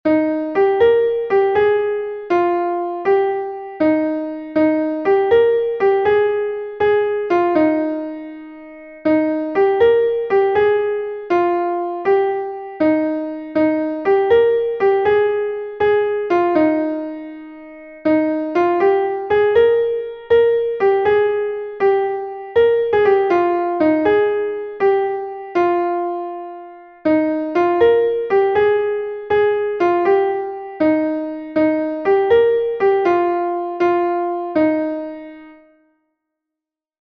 Ton Bale An Ollaika est un Bale de Bretagne